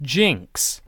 Jynx (/ˈɪŋks/